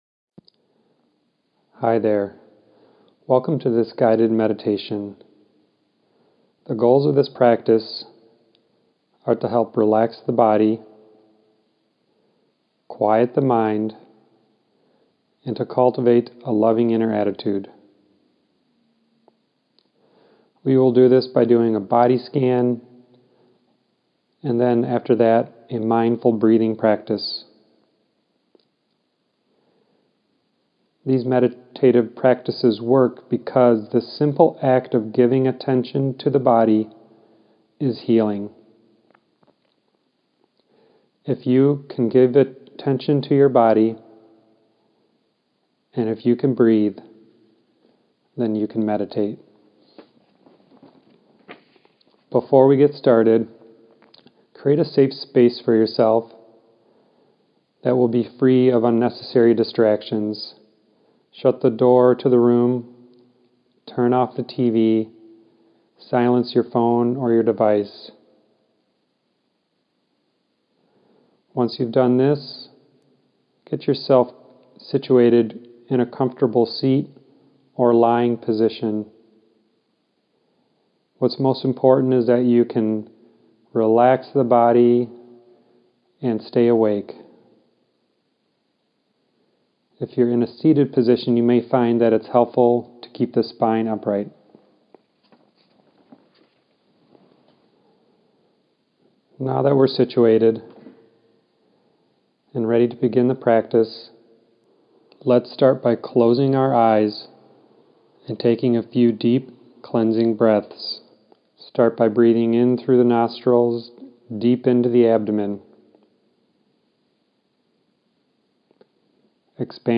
guided-meditation.mp3